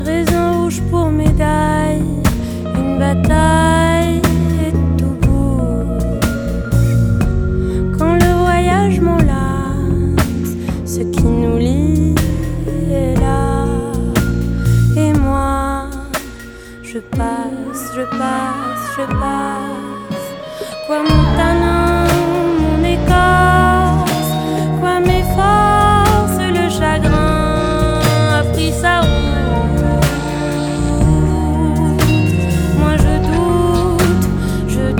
Жанр: Музыка из фильмов / Саундтреки